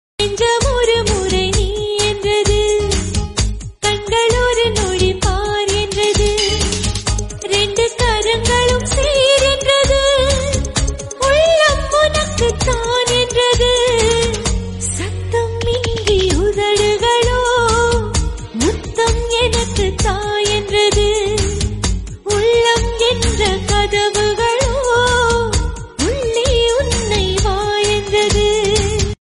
best flute ringtone download
romantic ringtone download
melody ringtone